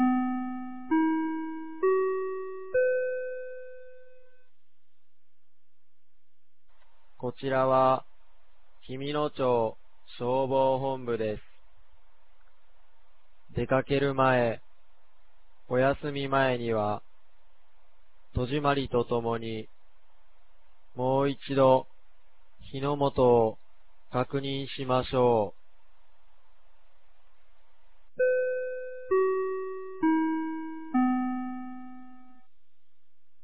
2025年09月06日 16時00分に、紀美野町より全地区へ放送がありました。